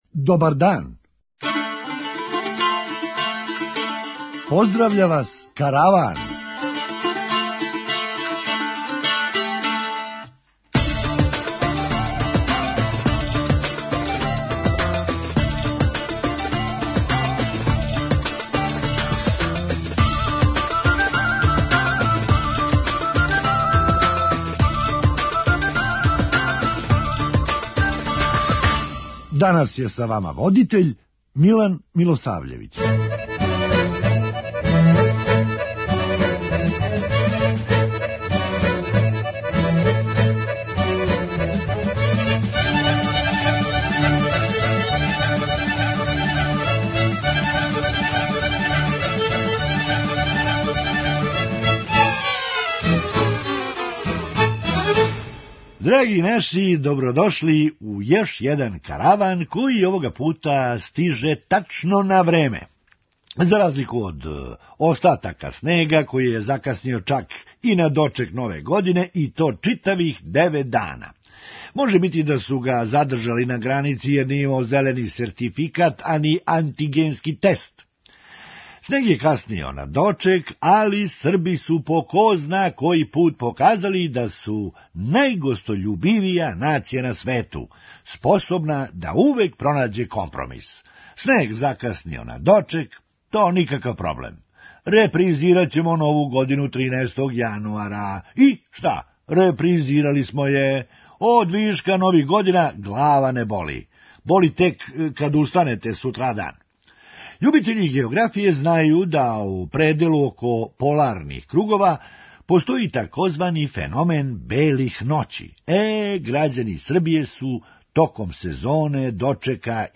Хумористичка емисија
На сву срећу, тај број је и даље већи од броја студената који се јавља за чишћење године. преузми : 8.98 MB Караван Autor: Забавна редакција Радио Бeограда 1 Караван се креће ка својој дестинацији већ више од 50 година, увек добро натоварен актуелним хумором и изворним народним песмама.